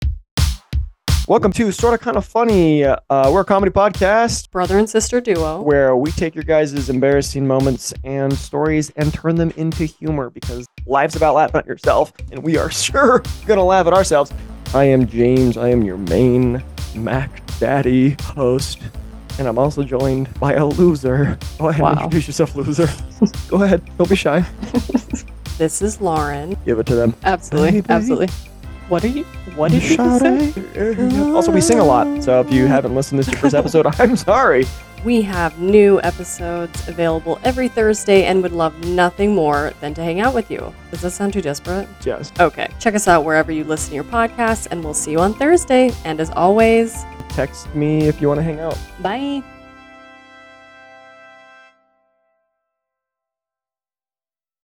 Welcome to your not-so family-friendly sibling comedy podcast. Featuring your younger brother, and older sister. Every Thursday, these comedic siblings talk about experiences people have had: embarrassing and hilarious, and find the humor.